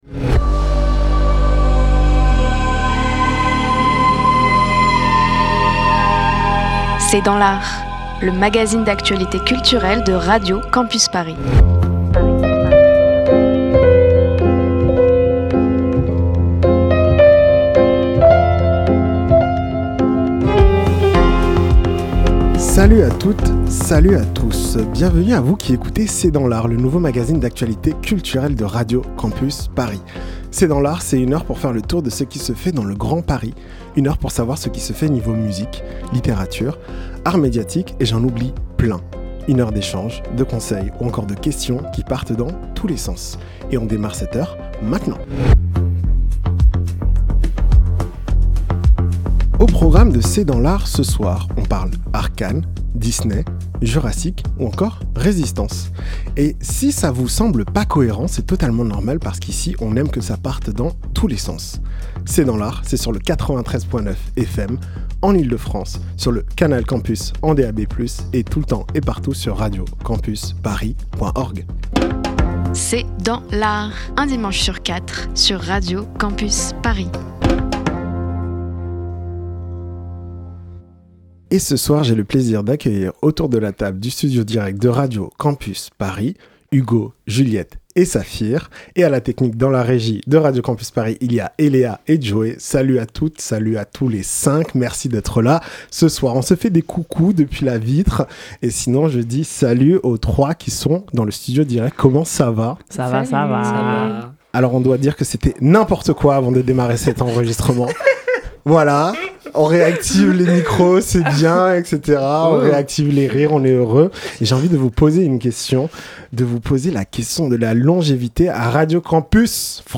C'est dans l'art, c'est le nouveau magazine d'actualité culturelle de Radio Campus Paris